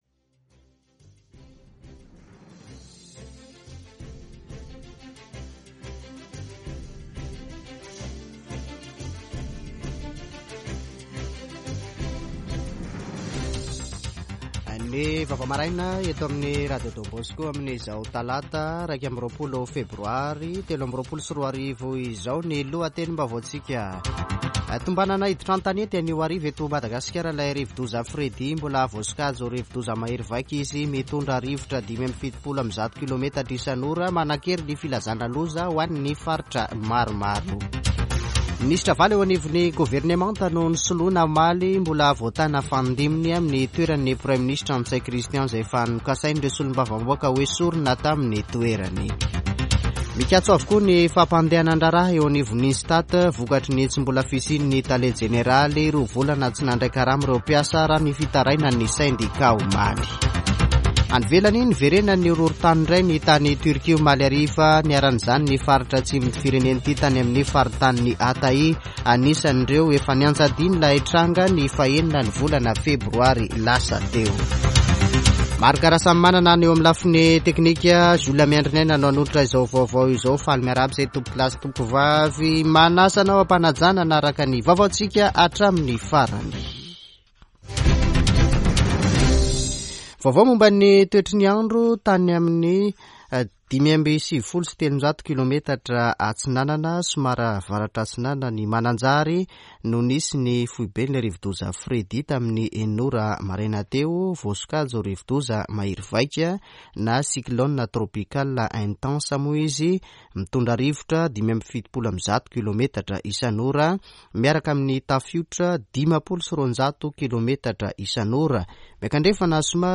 [Vaovao maraina] Talata 21 febroary 2023